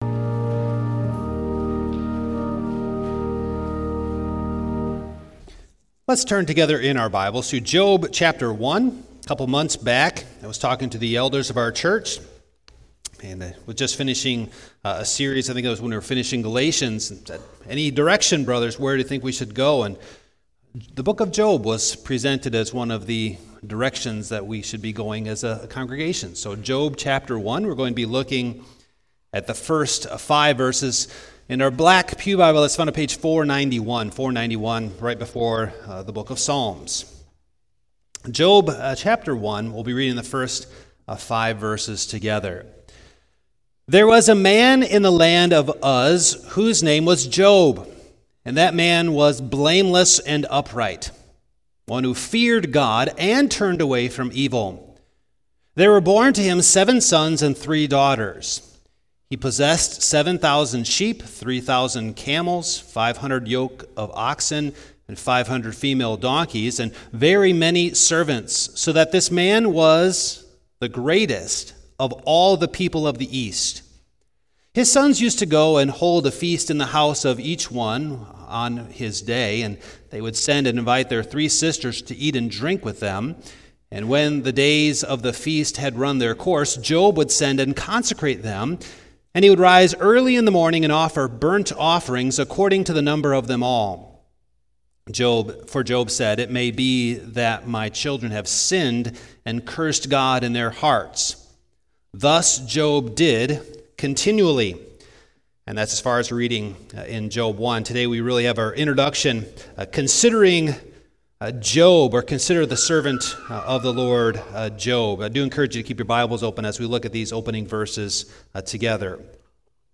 Sermons - Lynwood United Reformed Church - Page 6